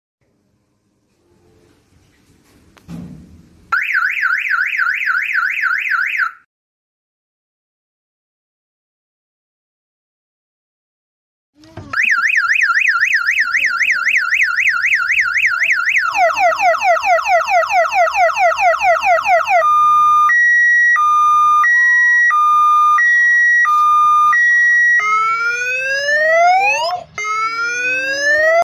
Alarm Mati Lampu Listrik